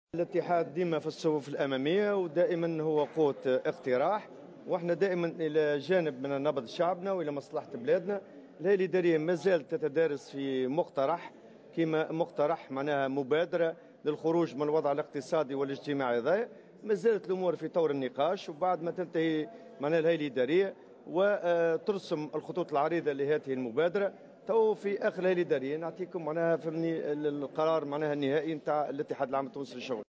A l'occasion de la réunion du comité administratif nationale de l'UGTT à Hammamet, Taboubi a souligné au micro de notre correspondante dans la région qu'il donnera plus de précisions sur ce sujet ultérieurement.